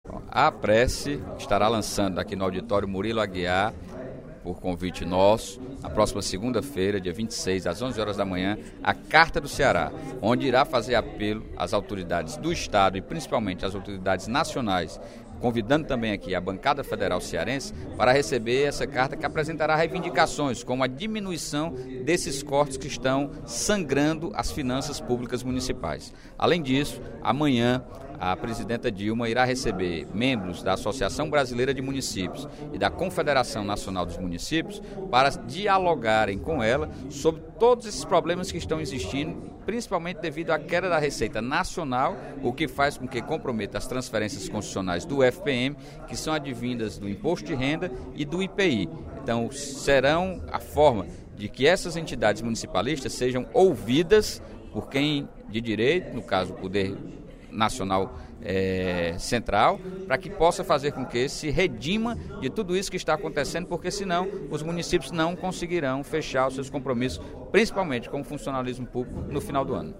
O deputado Sérgio Aguiar (Pros) fez pronunciamento, nesta quarta-feira (21/10), no primeiro expediente da sessão plenária, para anunciar que, na próxima segunda-feira (26), às 11 horas, dirigentes e prefeitos da Associação dos Municípios do Estado do Ceará (Aprece) farão, na sede da Assembleia Legislativa, o lançamento da Carta do Ceará.